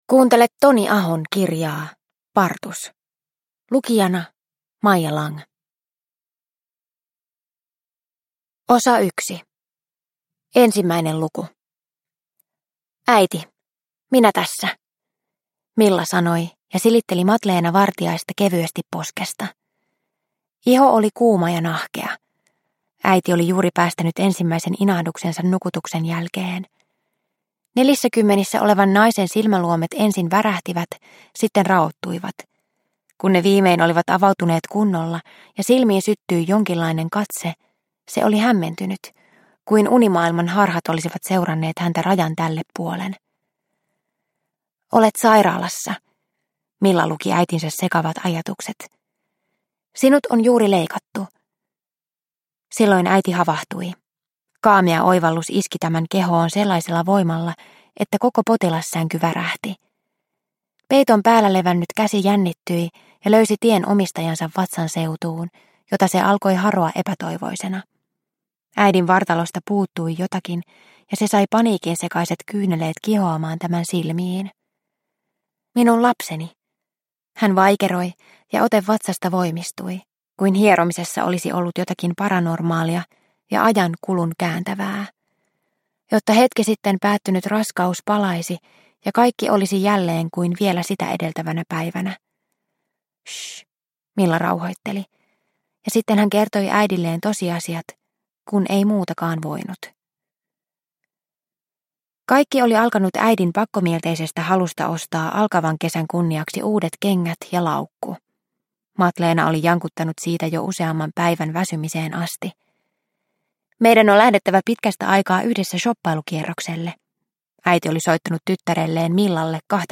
Partus – Ljudbok – Laddas ner